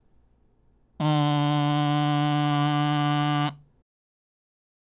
音色のイメージは、身長が数十cmの小人になったつもりでキンキンした音色が出るればOKです。
※喉頭を上げたグーの声(ん)